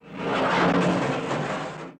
Trash Cans | Sneak On The Lot